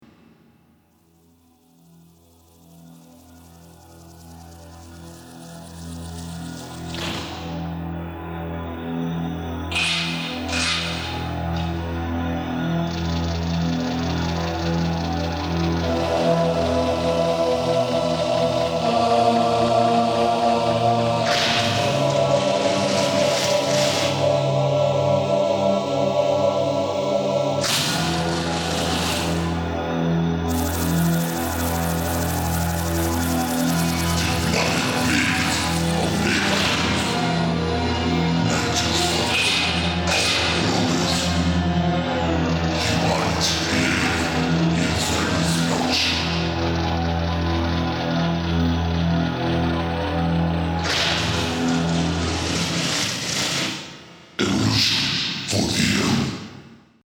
Death Metal
Lead Guitar
Drums